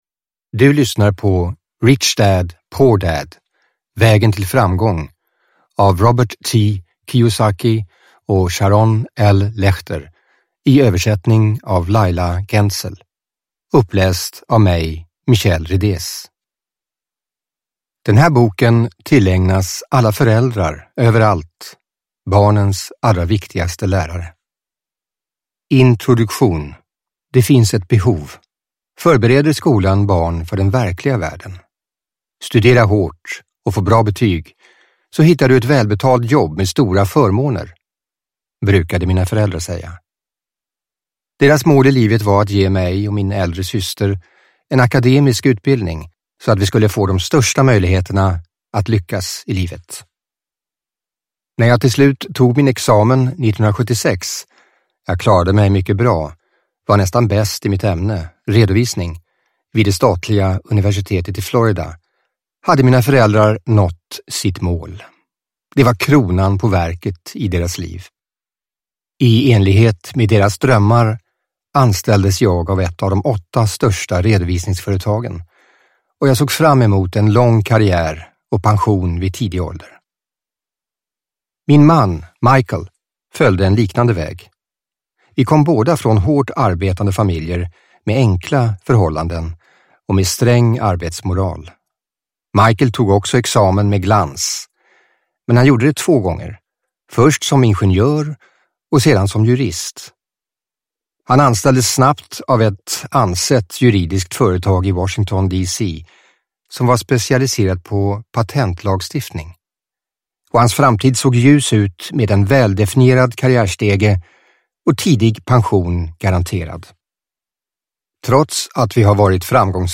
Rich Dad Poor Dad - vägen till ekonomisk framgång – Ljudbok – Laddas ner